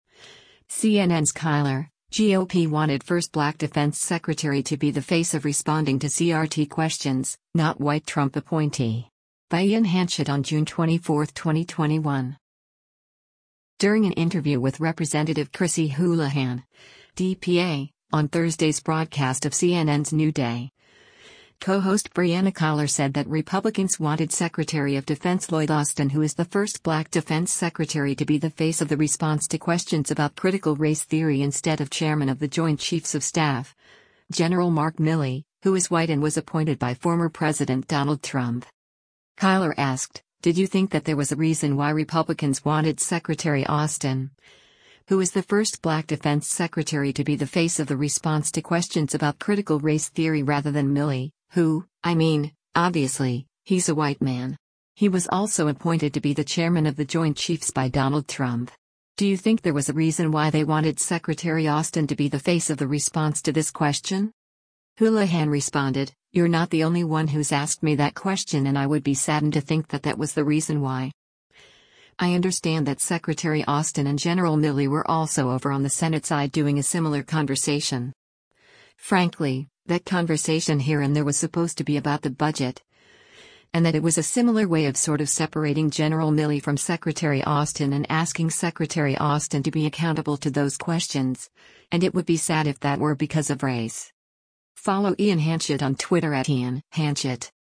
During an interview with Rep. Chrissy Houlahan (D-PA) on Thursday’s broadcast of CNN’s “New Day,” co-host Brianna Keilar said that Republicans wanted Secretary of Defense Lloyd Austin “who is the first black Defense Secretary to be the face of the response to questions about Critical Race Theory” instead of Chairman of the Joint Chiefs of Staff, Gen. Mark Milley, who is white and was appointed by former President Donald Trump.